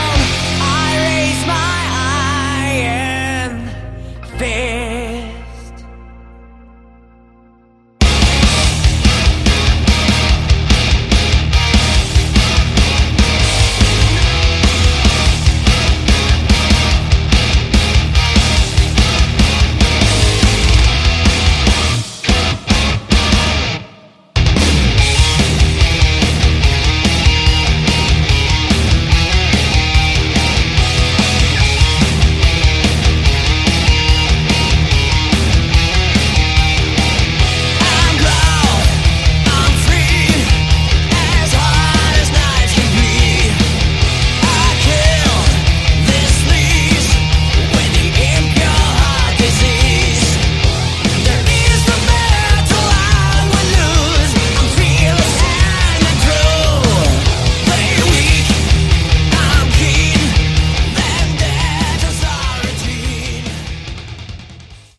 Category: Hard Rock
Guitars
Drums
Vocals
Bass